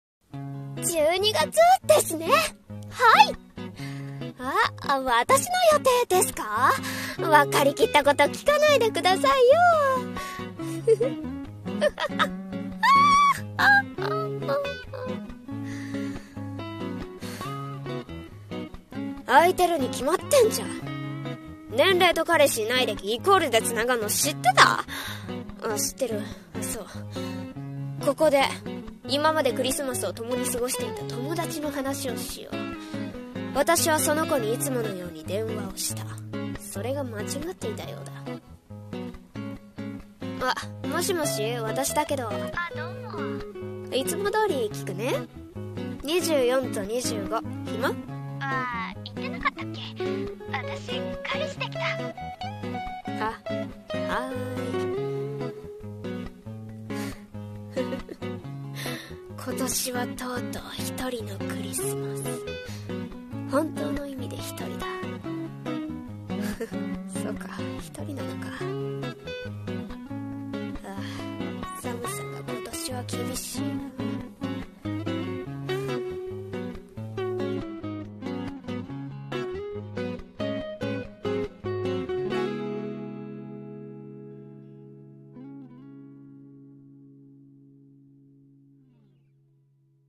【1人声劇】